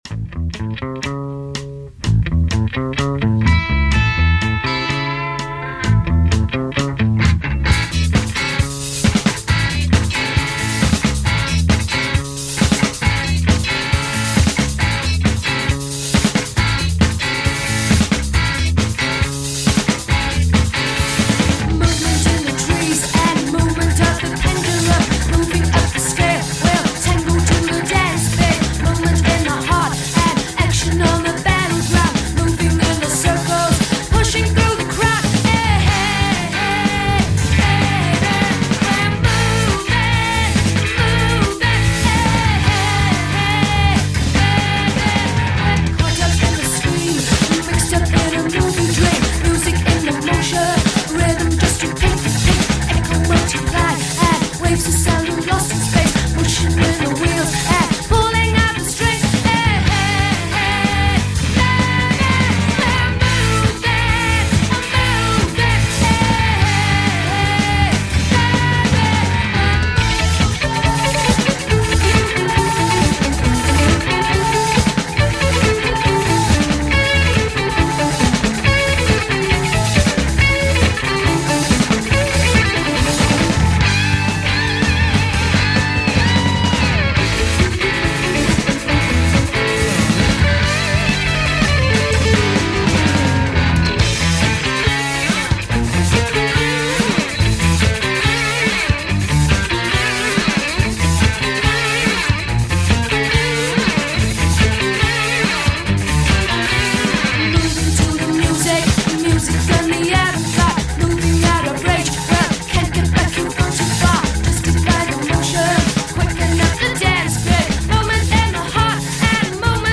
first-wave of Punk Bands from the formative days of 1976.